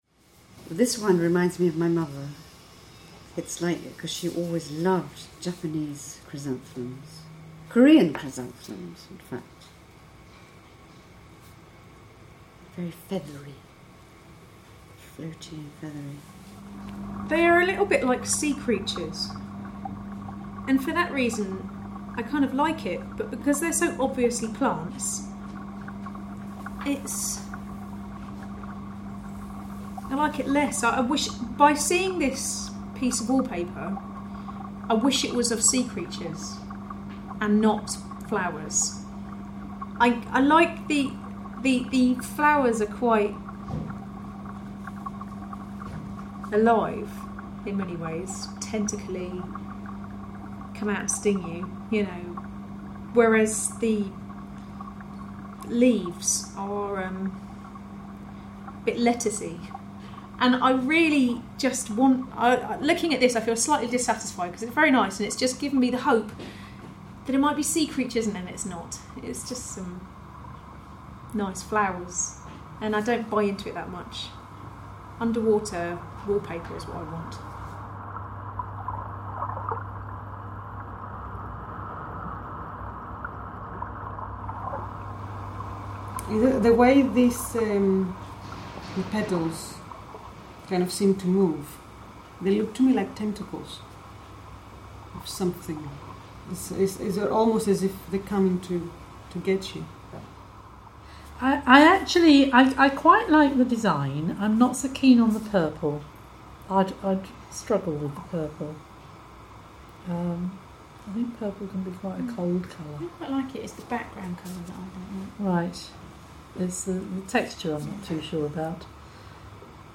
Field recordings made in different aquariums using hydrophones have been layered to underscore these ideas; to create a sonic equivalent to the visual, paper design.